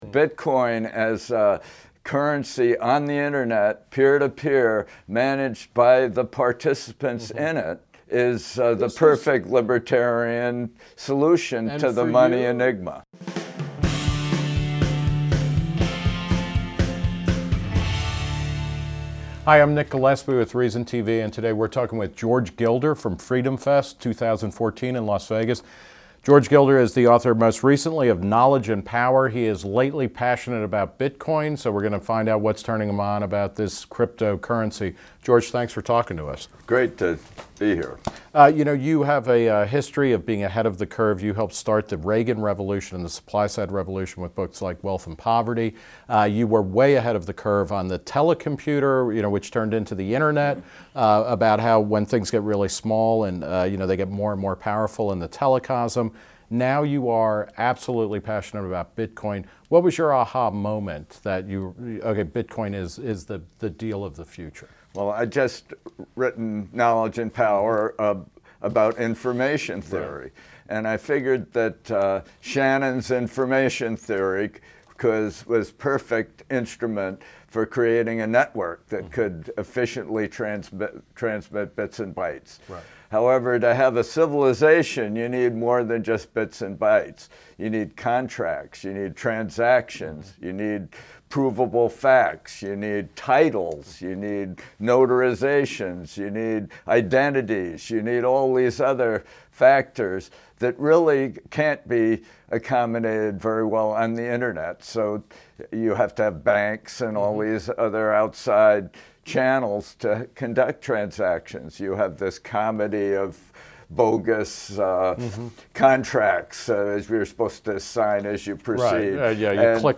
Reason TV's Nick Gillespie sat down with Gilder to talk about why he thinks Bitcoin is a revolutionary technology. The interview took place at FreedomFest 2014 , an annual convention for libertarians held each year in Las Vegas.